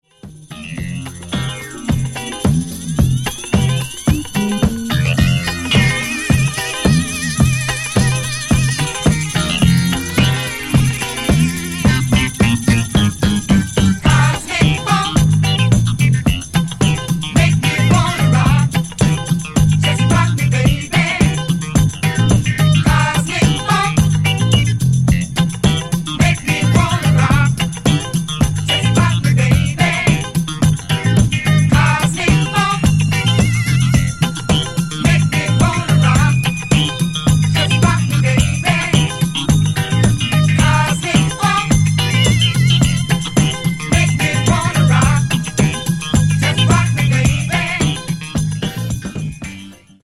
Genere:   Disco | Funk | Sunshine Sound